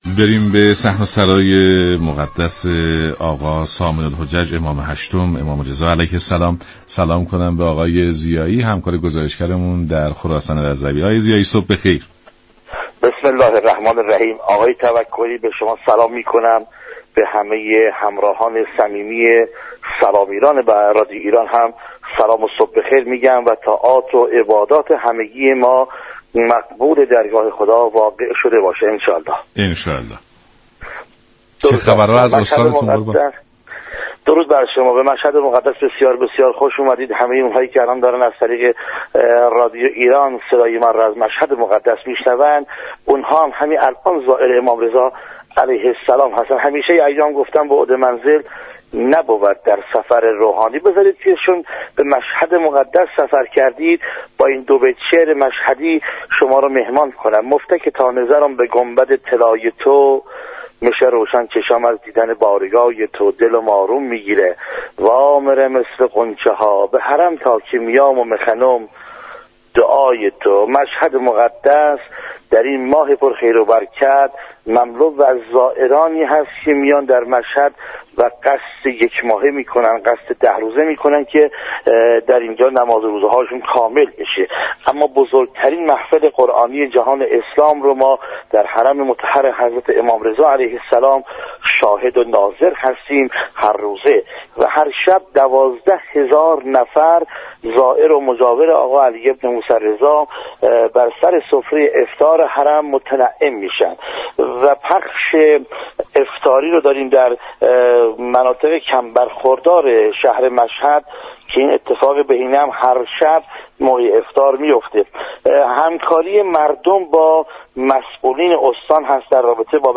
ارتباط تلفنی برقرار كرد.